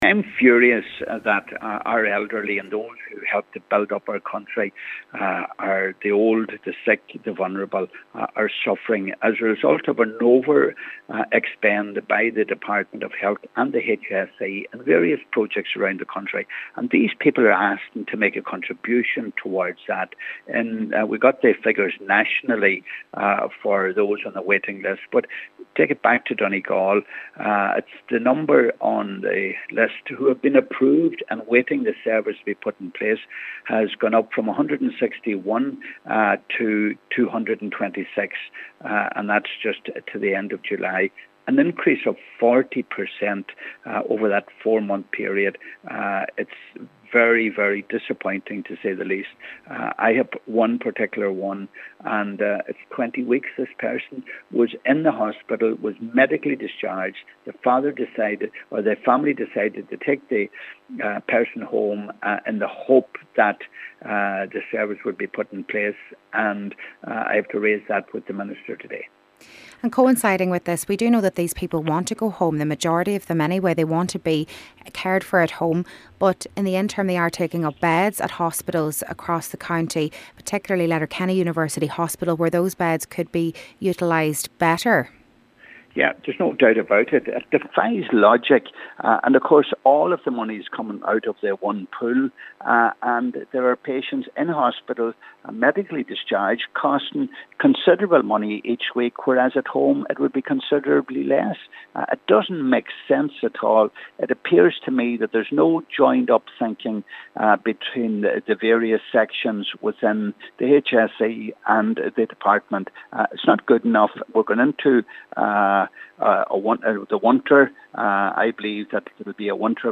Donegal Deputy Pat the Cope Gallagher says the crisis simply cannot be allowed to continue: